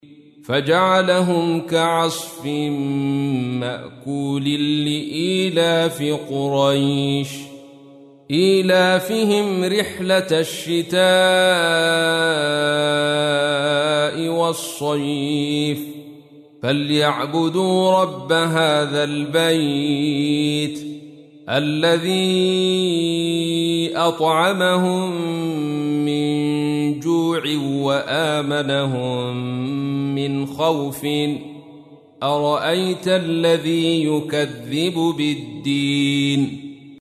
تحميل : 106. سورة قريش / القارئ عبد الرشيد صوفي / القرآن الكريم / موقع يا حسين